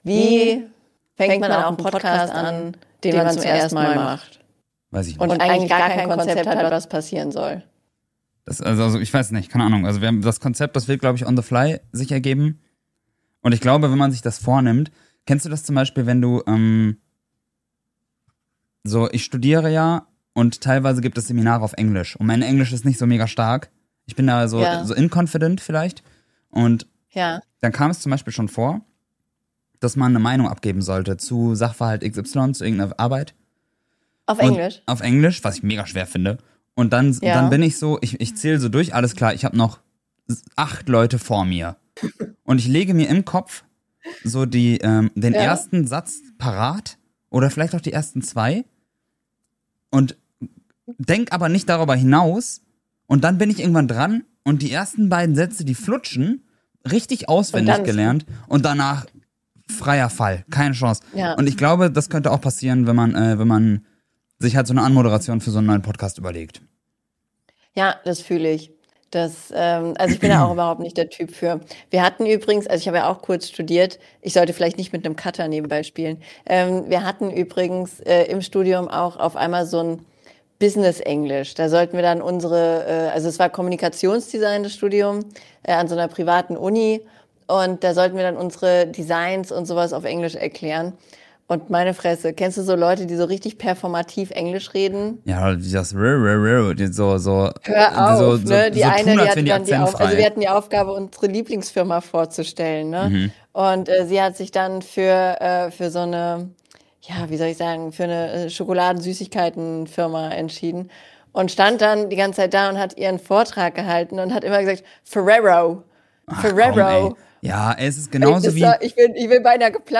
Im lockeren Plauderton reden wir über Unsicherheiten, schräge Designentscheidungen und wie Kleidung unser Selbstbewusstsein beeinflusst. Es geht um echte Erfahrungen, spontane Intrusive Thoughts und die kleinen Absurditäten des Alltags, die uns beschäftigen. Natürlich nehmen wir auch KI-Trends und Social Media aufs Korn – und fragen uns, ob Authentizität noch eine Chance hat.